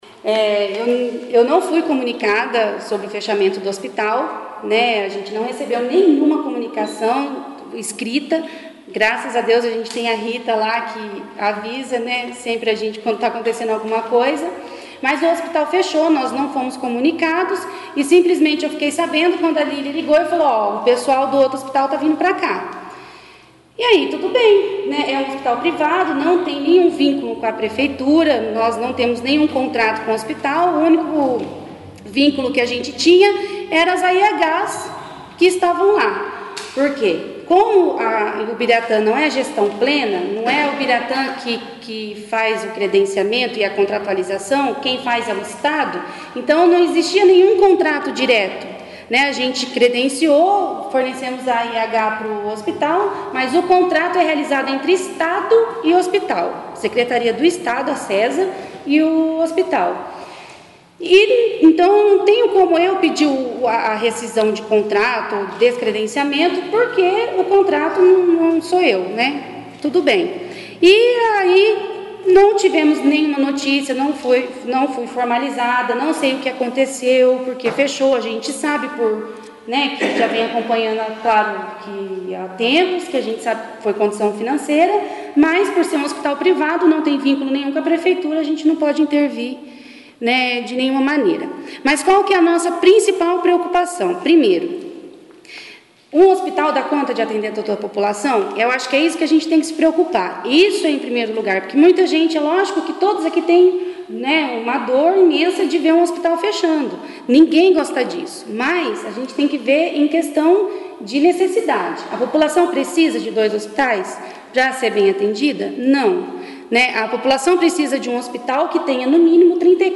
CLIQUE AQUI para ouvir o relato da secretária de Saúde, Cristiane Pantaleão